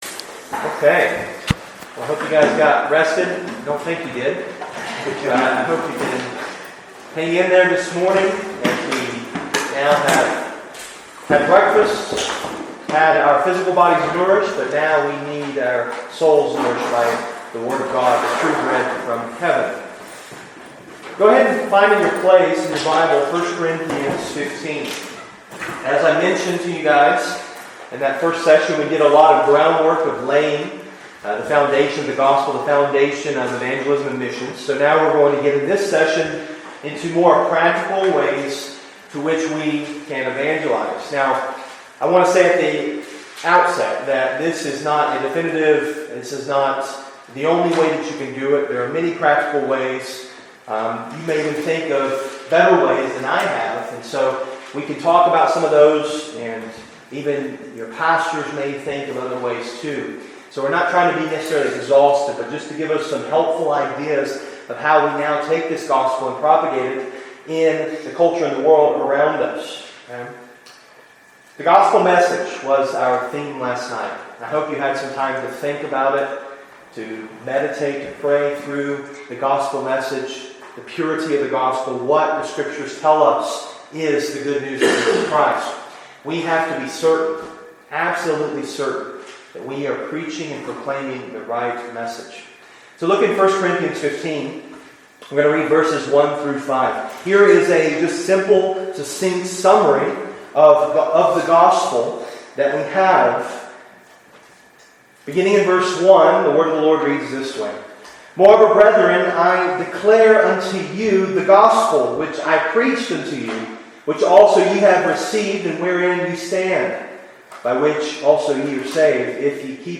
Part 1: Practical Evangelism | SermonAudio Broadcaster is Live View the Live Stream Share this sermon Disabled by adblocker Copy URL Copied!